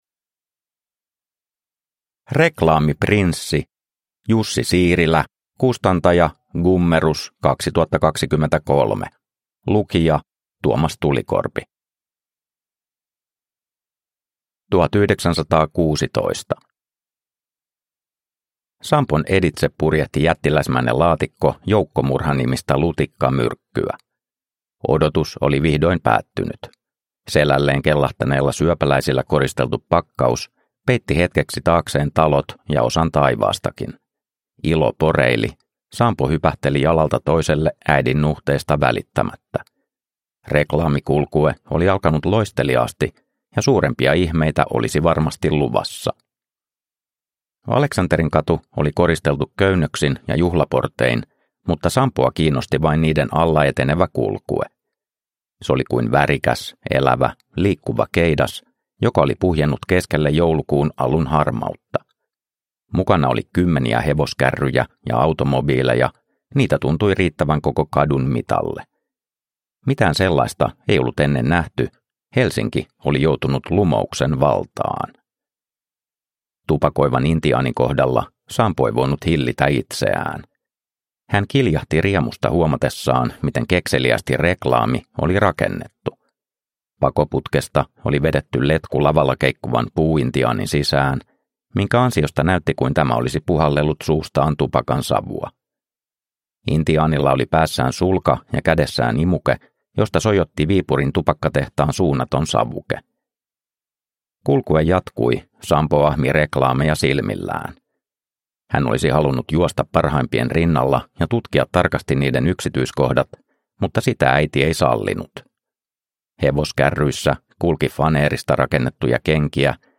Reklaamiprinssi – Ljudbok – Laddas ner